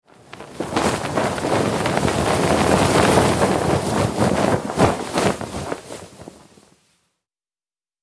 Погрузитесь в атмосферу морских путешествий с коллекцией звуков паруса: шелест натянутой ткани, ритмичный стук волн о борт, крики чаек.
Звук когда ветер резко подхватил парус